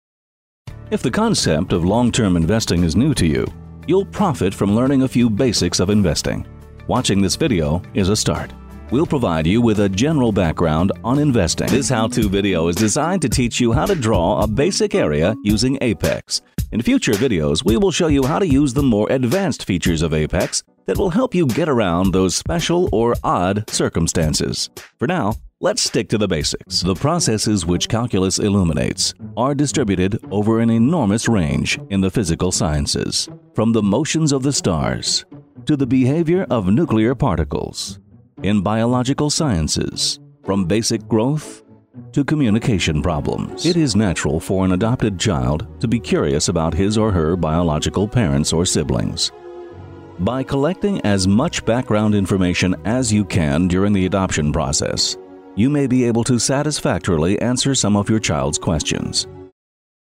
Season voice actor, producer and director as well as character voice specialist.
mid-atlantic
middle west
Sprechprobe: eLearning (Muttersprache):